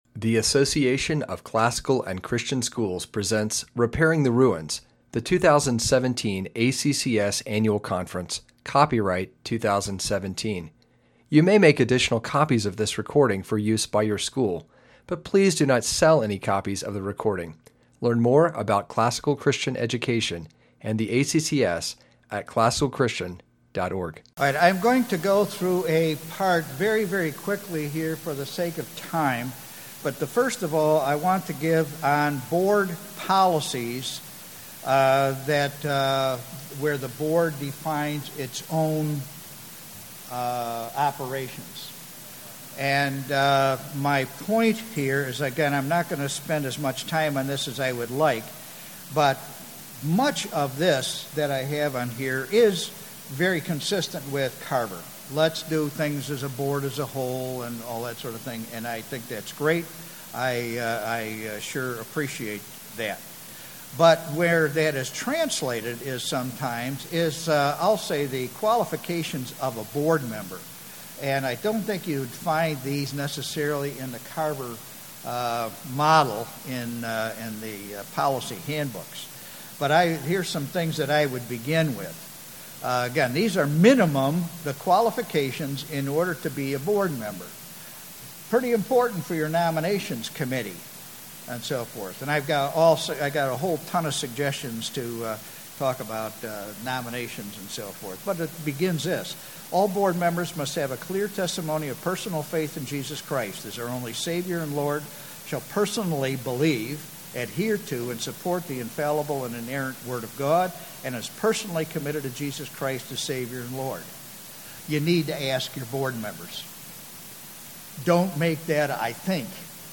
2017 Leaders Day Talk | 0:40:55 | Leadership & Strategic
Jan 9, 2019 | Conference Talks, Leaders Day Talk, Leadership & Strategic, Library, Media_Audio | 0 comments